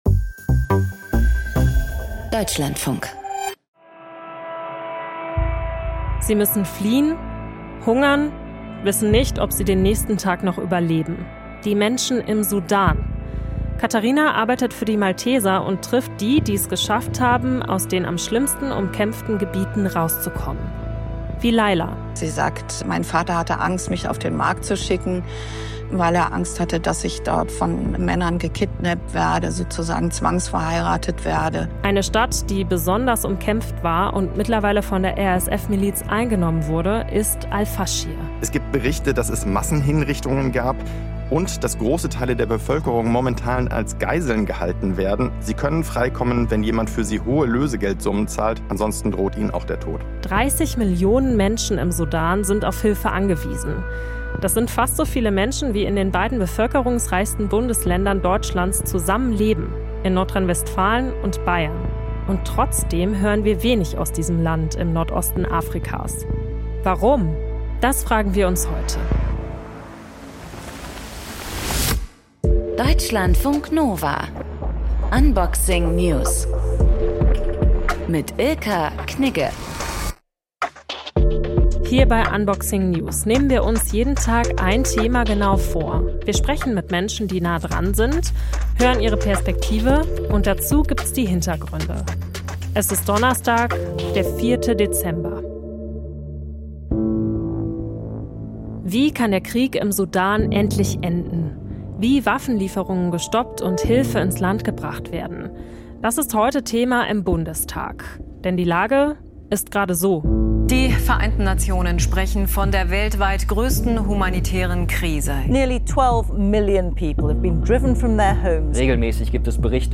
Gesprächspartnerin